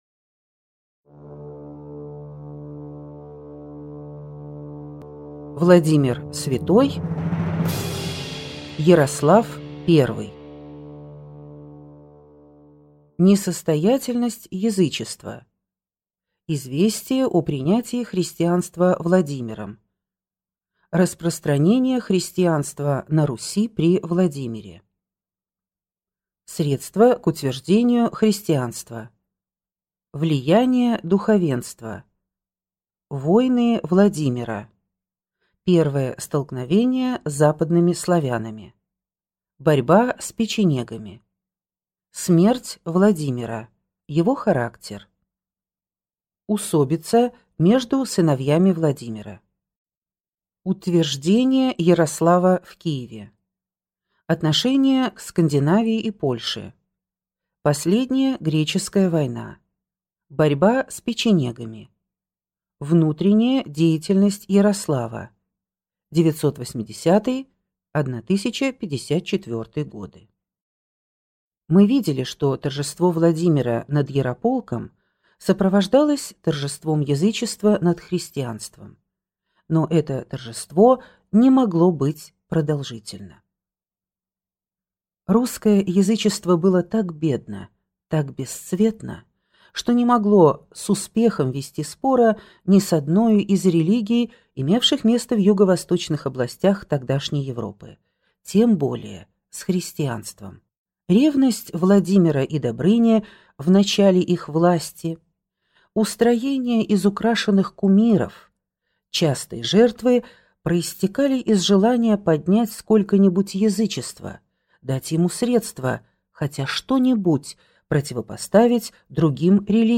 Аудиокнига Князья Владимир Святой и Ярослав I | Библиотека аудиокниг